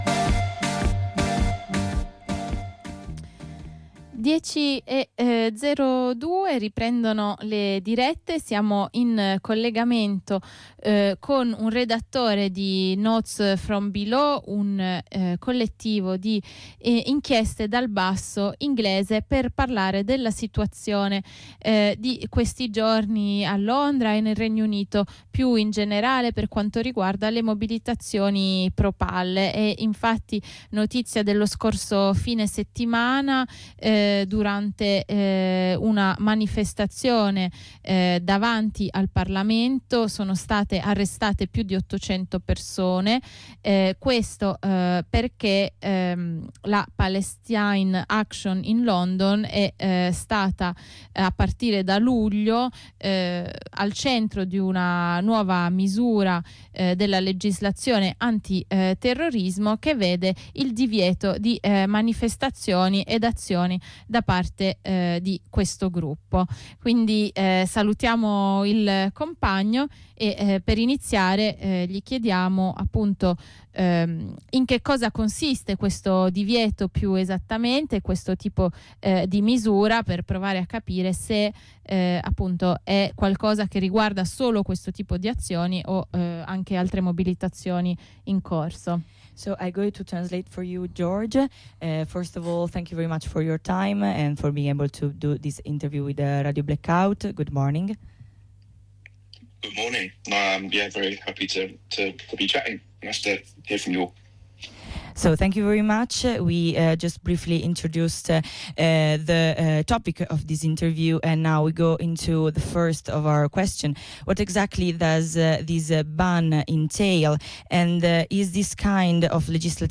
Sono state arrestate più di 800 persone lo scorso fine settimana, durante un’azione per la Palestina davanti al parlamento del Regno Unito. Questi arresti sono legati ai limiti imposti al gruppo Palestine Action attraverso un divieto (legislazione anti terrorismo) portato avanti da luglio. Ne parliamo con un compagno del collettivo di inchiesta Notes From Below che ci descrive il tipo di misura legislativa dispiegata e le sue conseguenze.